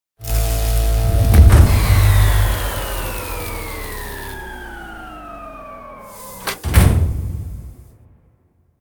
poweroff.ogg